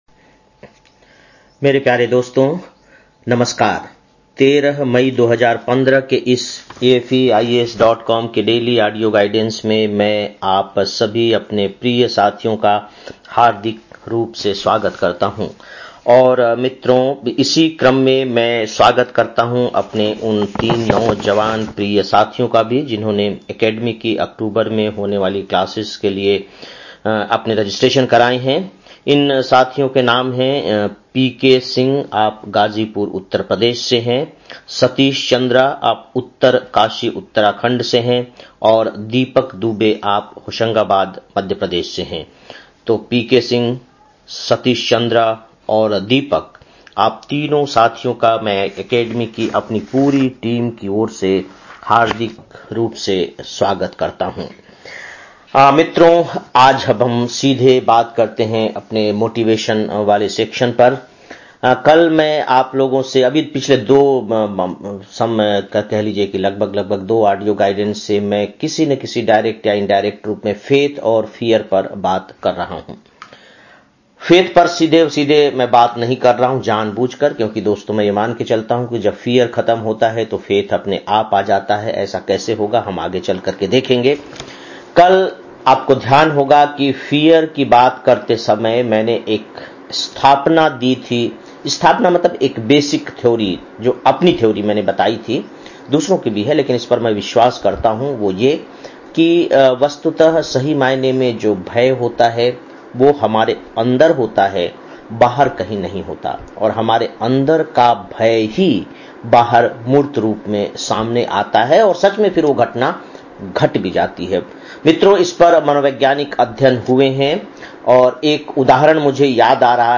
13-05-15 (Daily Audio Lecture) - AFEIAS